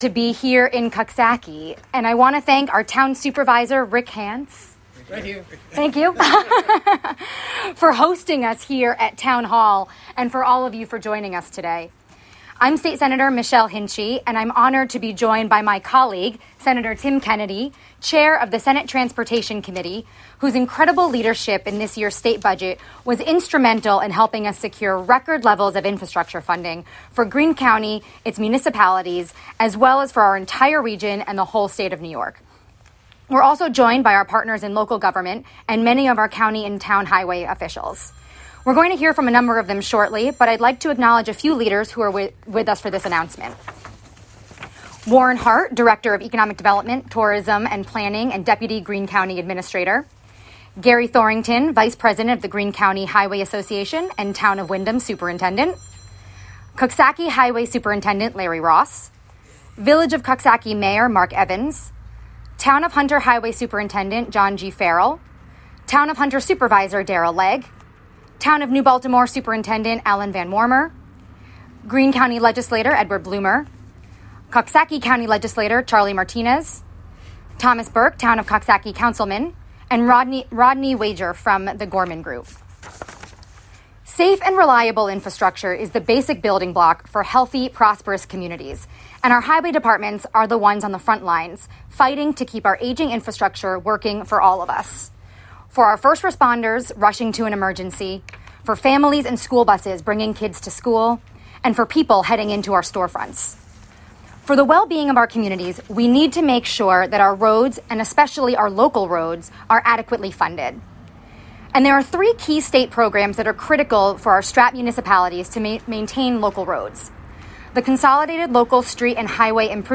Press Conference from State Senator Michelle Hinchey in Coxsackie about highway funding.
Recorded from a live WGXC webstream. Press conference in Coxsackie on April 28, 2021.